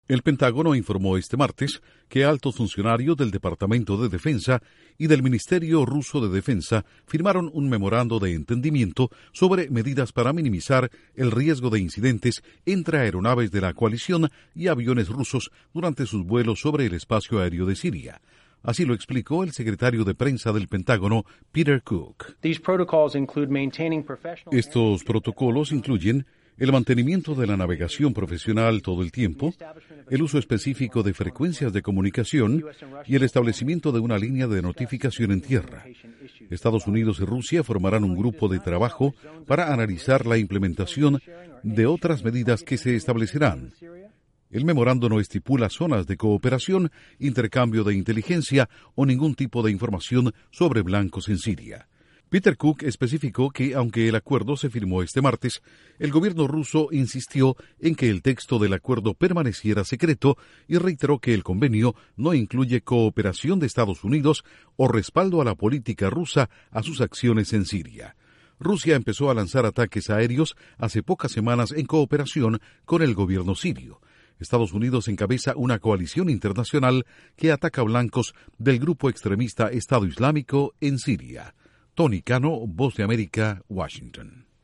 Estados Unidos y Rusia firman un acuerdo para minimizar accidentes aéreos durante sus incursiones en Siria. Informa desde la Voz de América en Washington